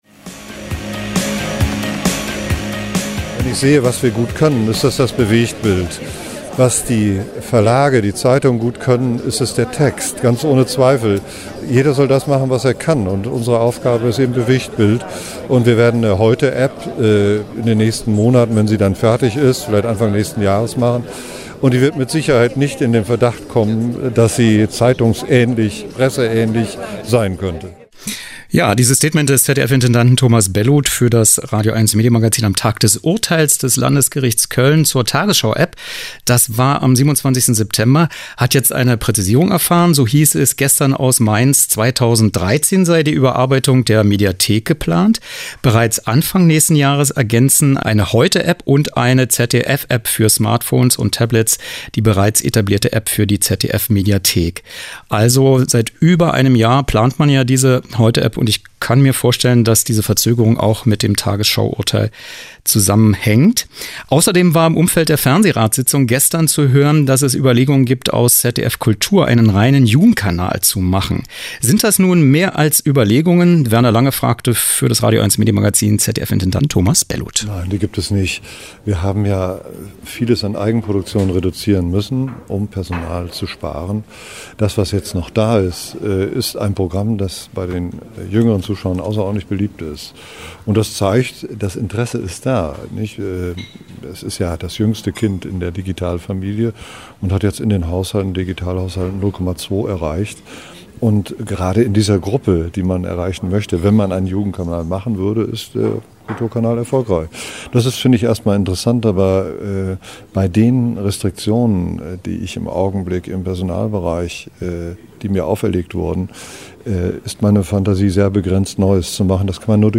O-Ton: Dr. Thomas Bellut, ZDF-Intendant (27.09.2012)
Interview mit Dr. Thomas Bellut, ZDF-Intendant am Rande der ZDF-Fernsehratssitzung in Mainz am 12.10.2012